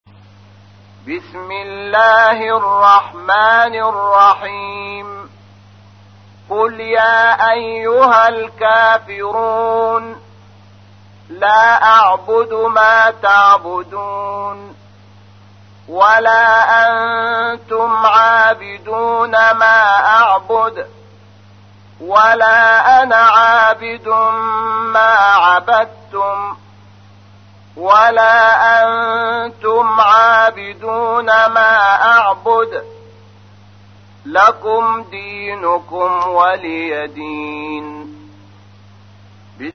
تحميل : 109. سورة الكافرون / القارئ شحات محمد انور / القرآن الكريم / موقع يا حسين